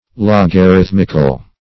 Logarithmic \Log`a*rith"mic\, Logarithmical \Log`a*rith"mic*al\,